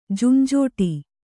♪ junjōṭi